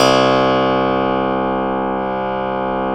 53s-pno01-C0.aif